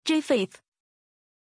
Pronunciation of Japheth
pronunciation-japheth-zh.mp3